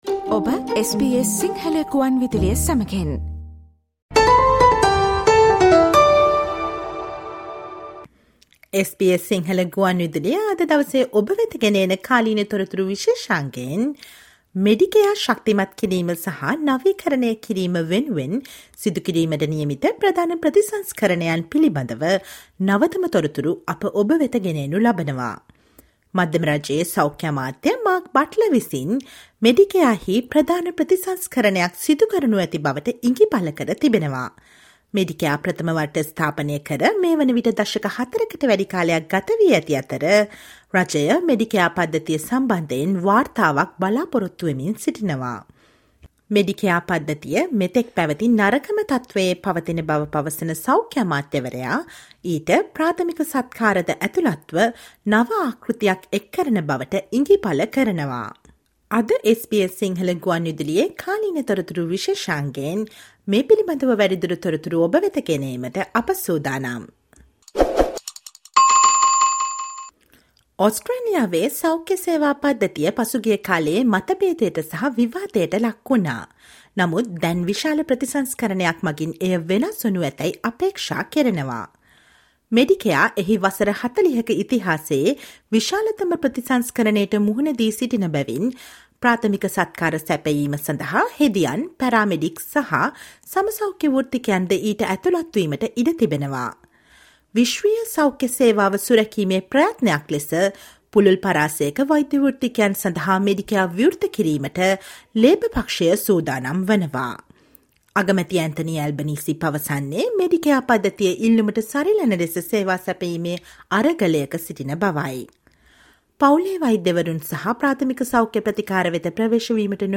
Listen to the SBS Sinhala radio current affair feature on new model of Medicare as it faces the biggest overhaul in its 40-year history including nurses, paramedics and other allied health professionals to deliver primary care.